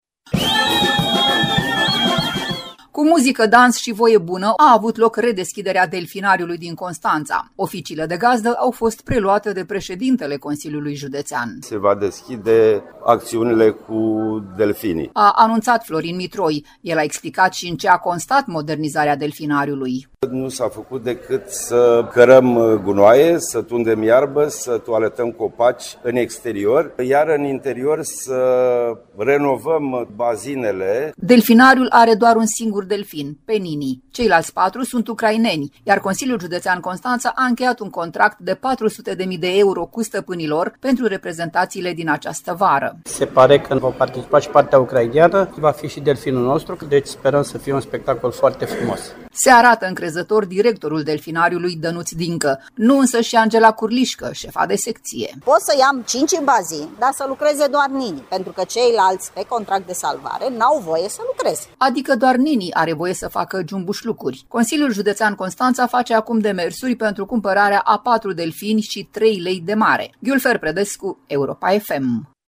Cu muzică, dans și voie bună, oferite de fanfara Regiei de Apă și de dansatorii ansamblului Brâulețul al CJ, a avut loc redeschiderea Delfinariului din Constanța, proaspăt modernizat.
Oficiile de gazdă au fost preluate de președintele Consiliului Județean Constanța: